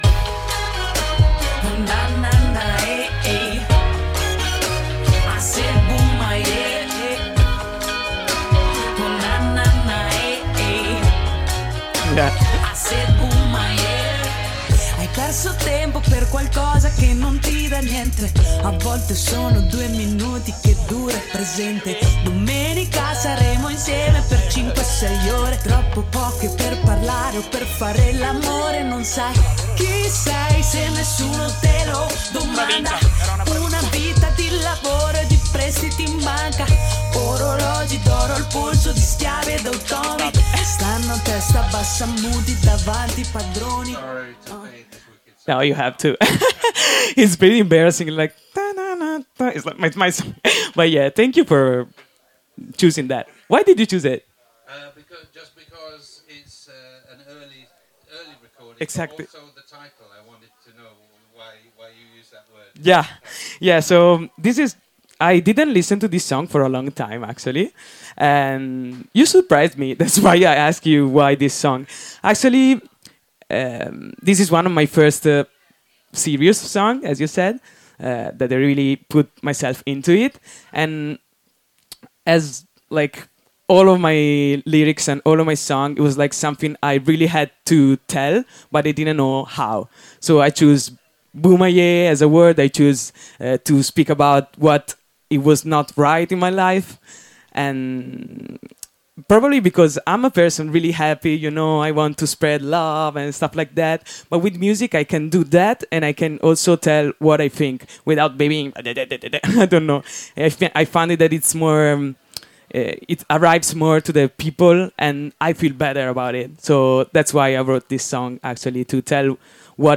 Un’intervista intensa e luminosa, registrata direttamente dalla Beach Yard, dove si intrecciano:
✊🏼 Il ruolo delle voci femminili nella scena consapevole italiana 🌍 La sua visione della musica come strumento di guarigione, lotta e comunità In sottofondo, le vibrazioni del Bababoom: roots, dub e hip-hop , tra sabbia, sole e parole che risuonano.